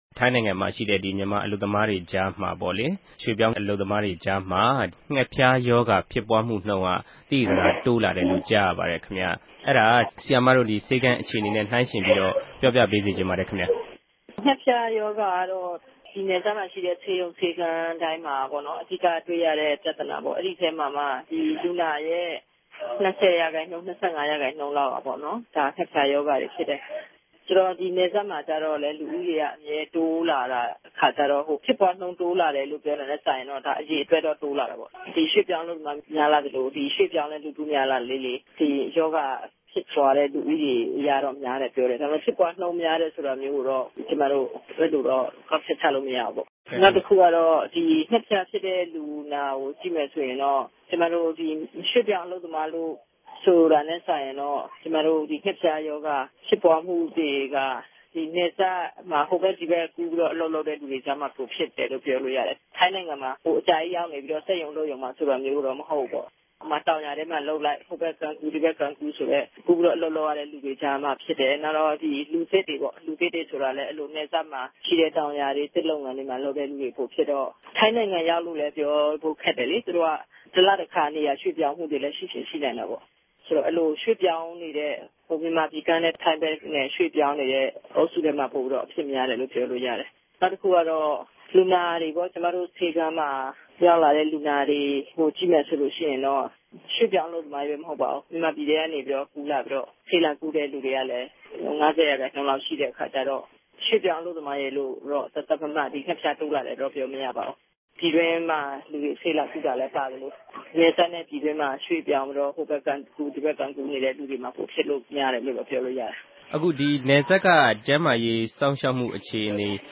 ဒေၝက်တာစင်သီယာမောငိံြင့် အမေးအေူဖ။